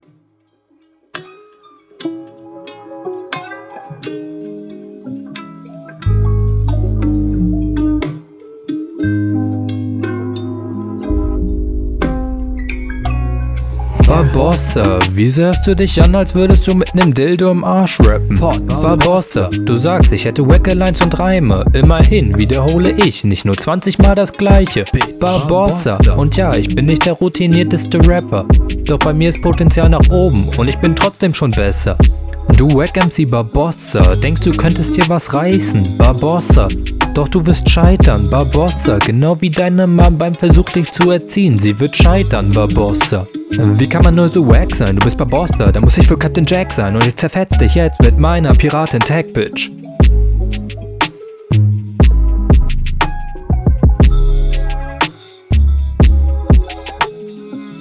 Solo Battles
Bist etwas zu leise, das hilft in deinem Fall, weil dadurch weniger auffällt, dass du …
Hängst mit dem Flow hinterher, denke da fehlt die Routine einfach.